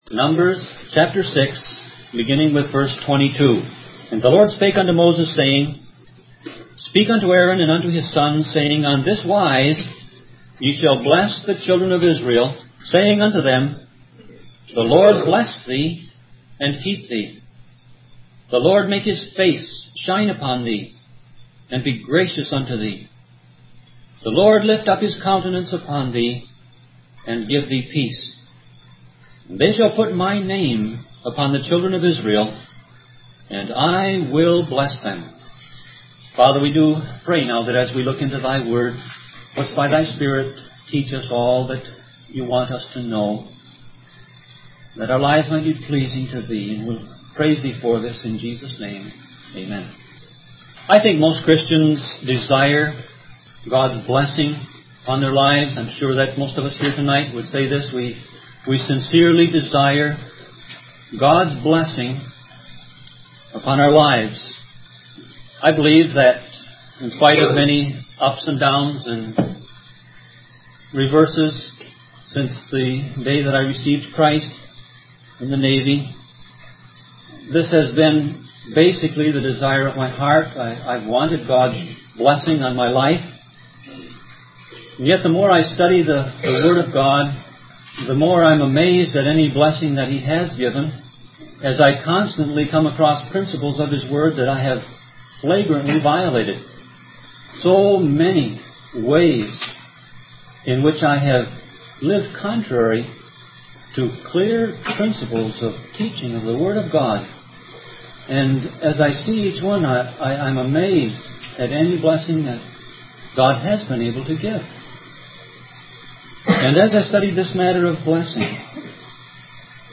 Sermon Audio Passage: Numbers 6:22-27 Service Type